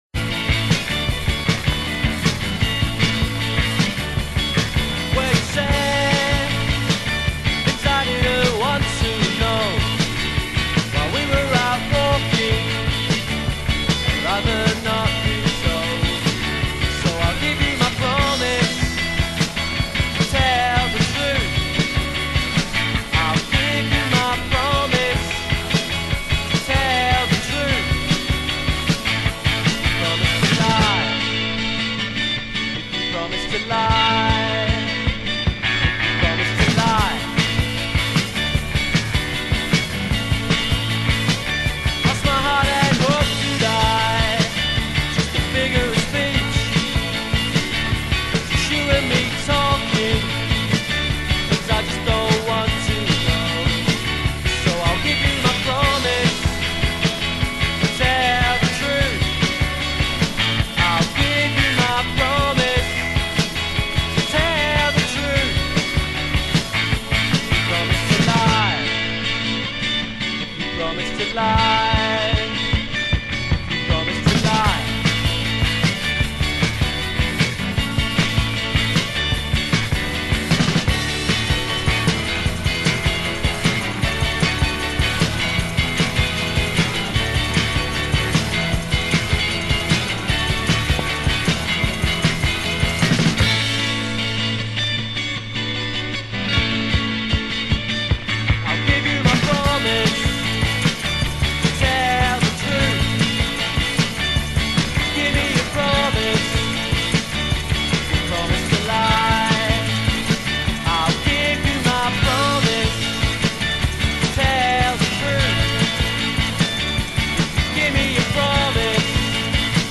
That fantastic lo-fi jangly gem.
recorded in Kingston (Surrey, not Jamaica:) Xmas 1986